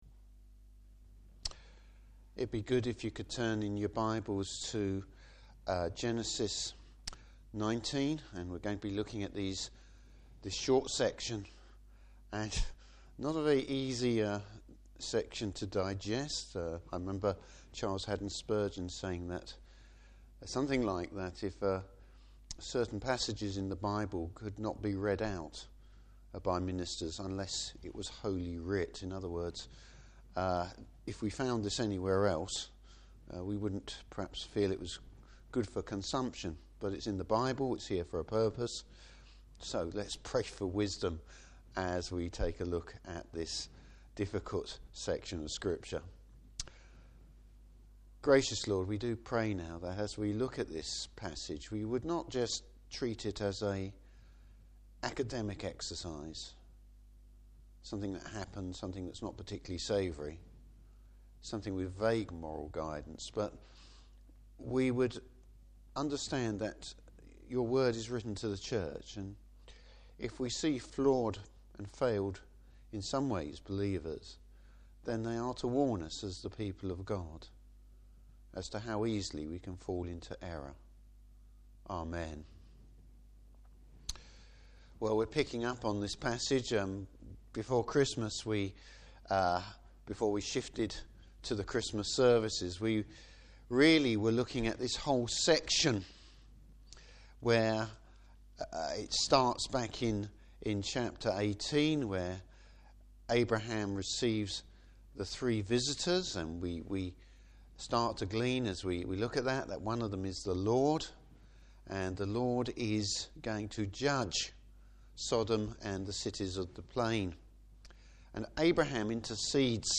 Service Type: Evening Service Lot’s failures, God’s Grace!